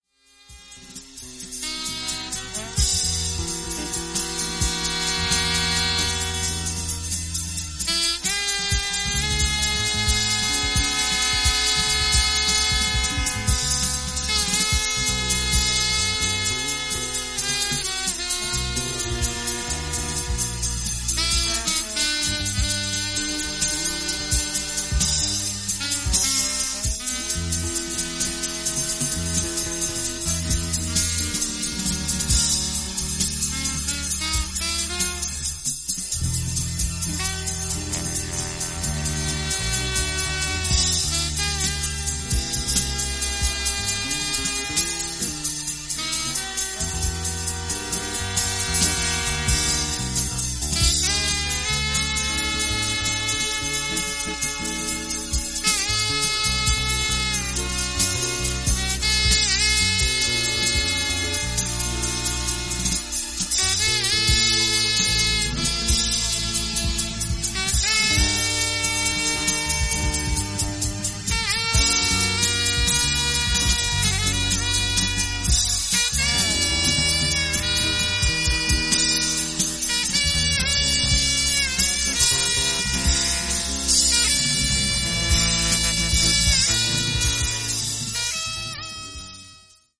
フリージャズ〜クロスオーヴァーの影響をもろに反映した